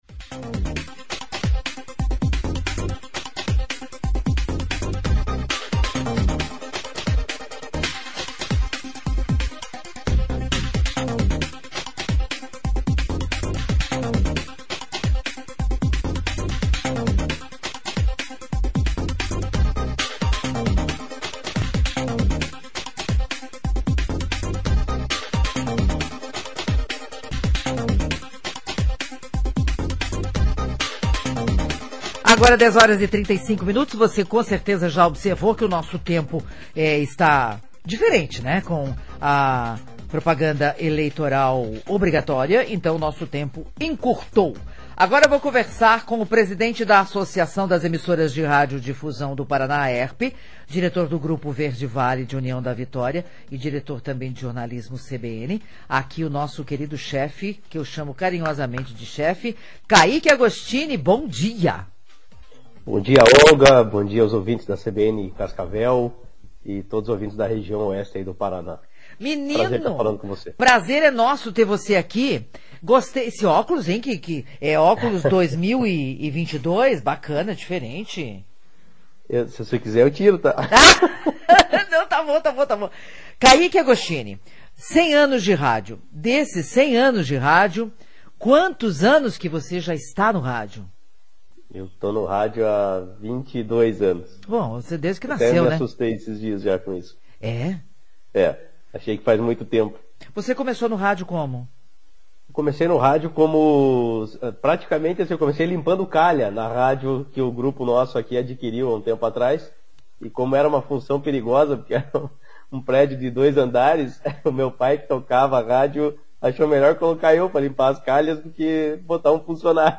entrevista sobre a evolução do rádio nos ultimos 100 anos.